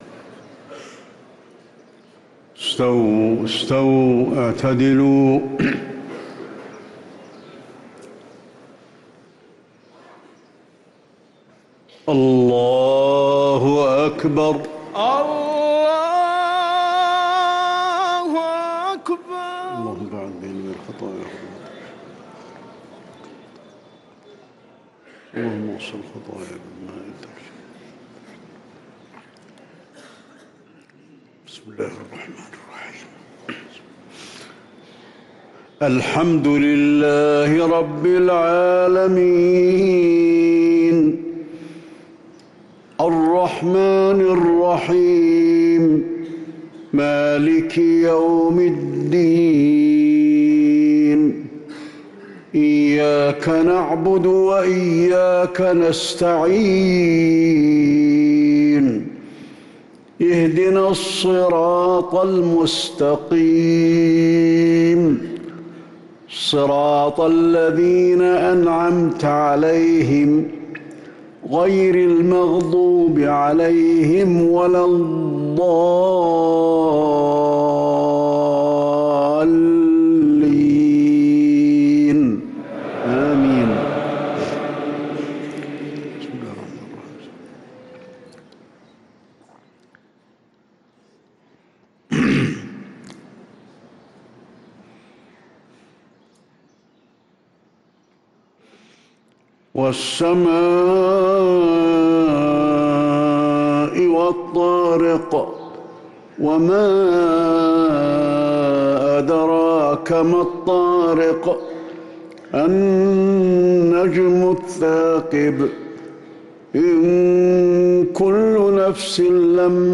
صلاة المغرب للقارئ علي الحذيفي 2 رمضان 1444 هـ
تِلَاوَات الْحَرَمَيْن .